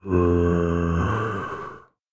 zombie2.wav